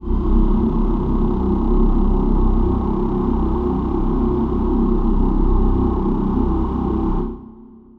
Choir Piano